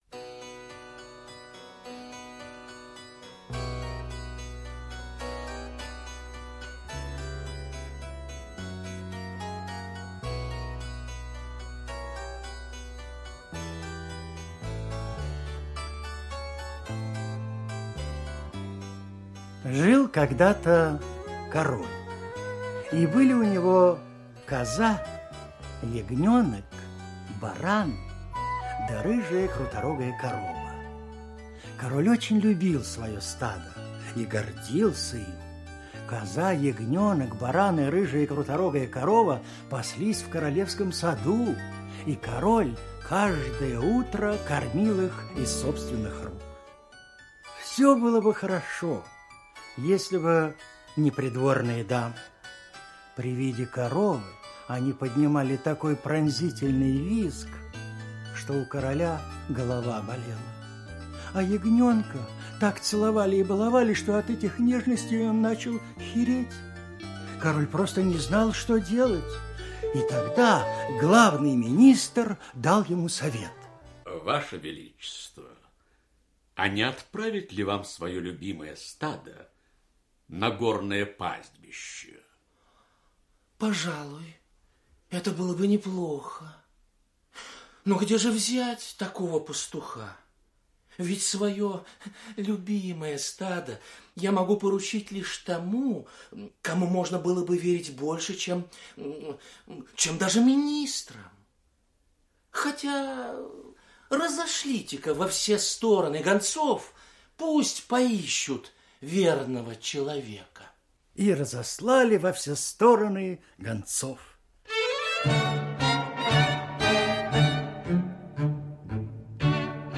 Массаро Правда - итальянская аудиосказка - слушать онлайн